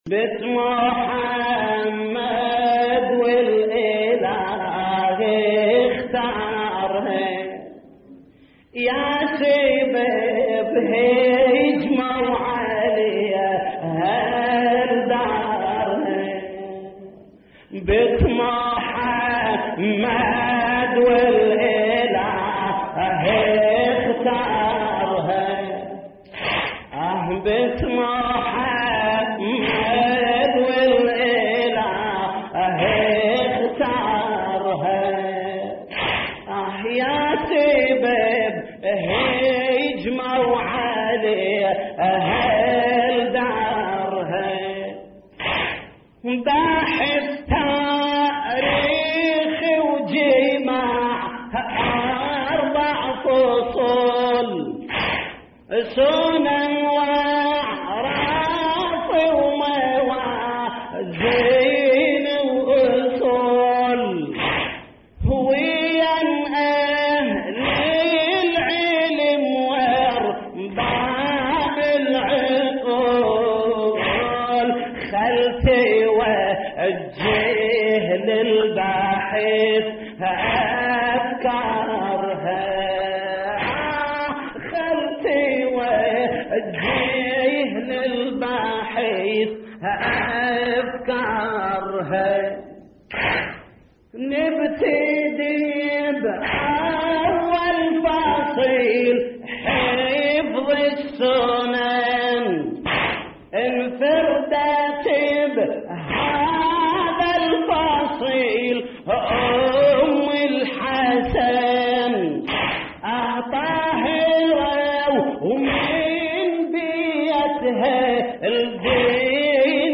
تحميل : بنت محمد والإله اختارها يا سبب هجمو عليها لدارها / الرادود جليل الكربلائي / اللطميات الحسينية / موقع يا حسين